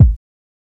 • 2000s Subtle Reverb Kick Sample G# Key 244.wav
Royality free bass drum single hit tuned to the G# note. Loudest frequency: 108Hz